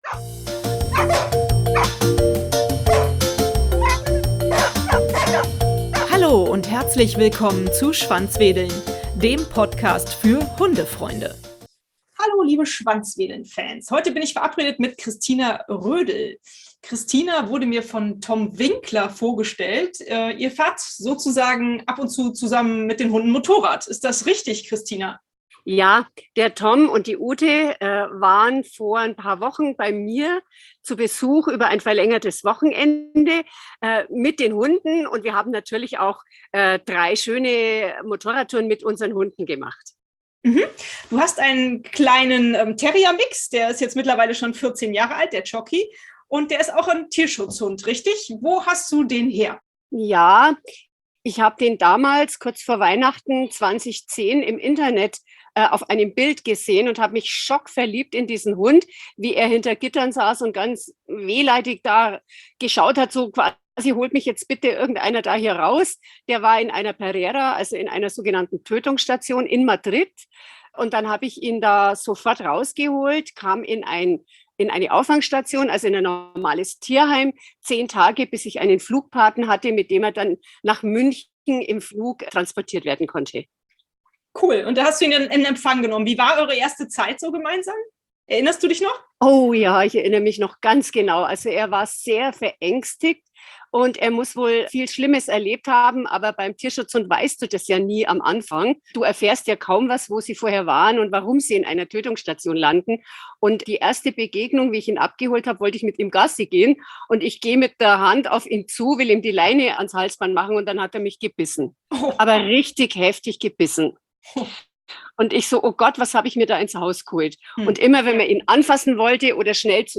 Lasst Euch überraschen von diesem spannenden Interview: